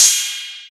Index of /90_sSampleCDs/Club_Techno/Percussion/Cymbal
Ride_04.wav